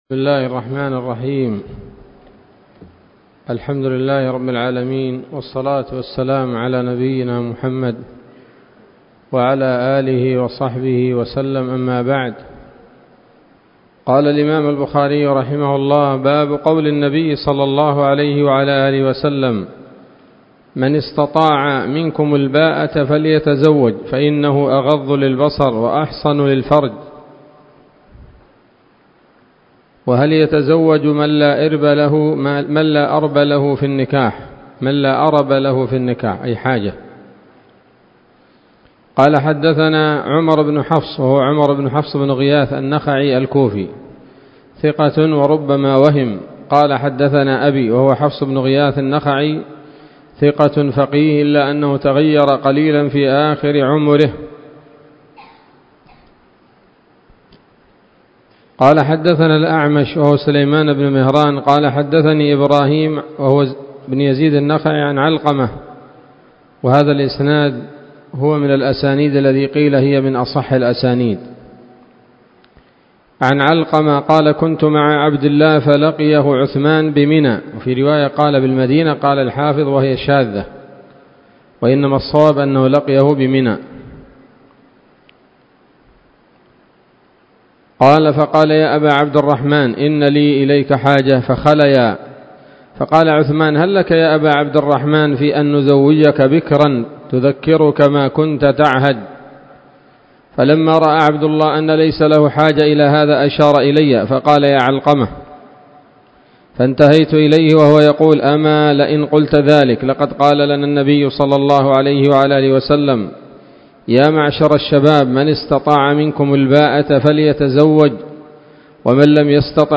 الدرس الثاني من كتاب النكاح من صحيح الإمام البخاري